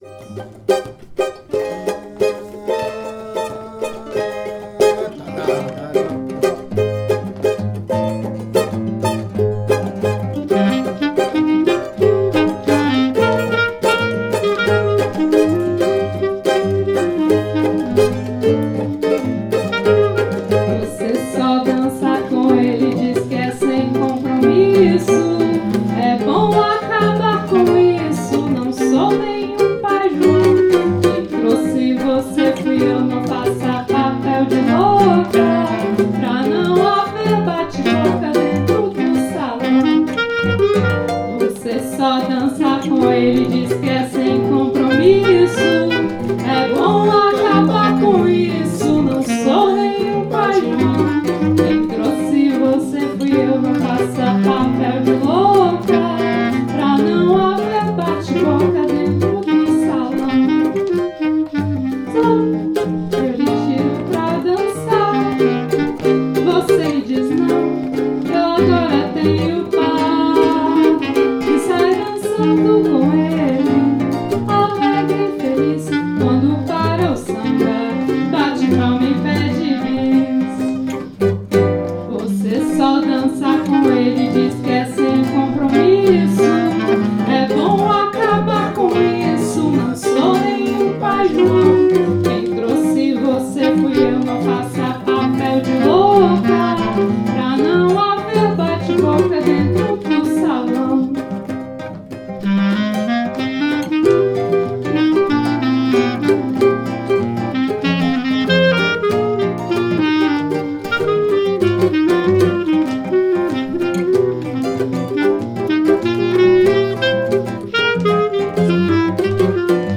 Rec atelier
Session sans percussions
Sem_Compromisso_sans_percus.mp3